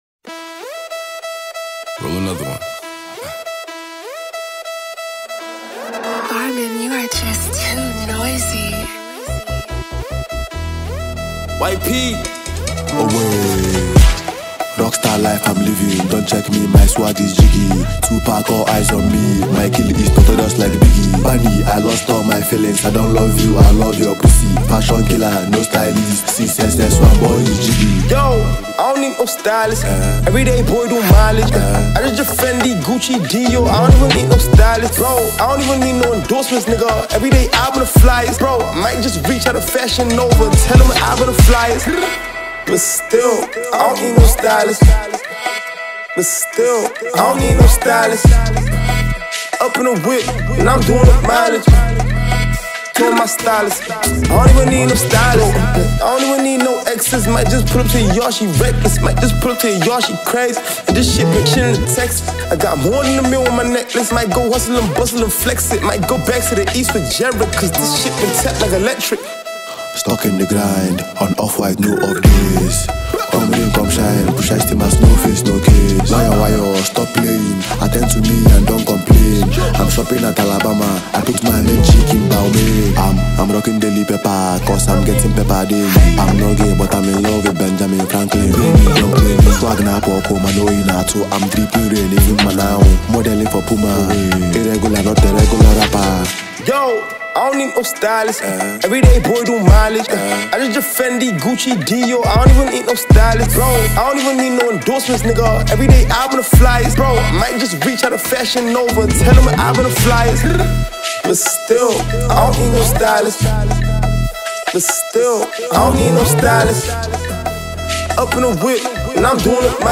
a Nigerian rapper, singer, and songwriter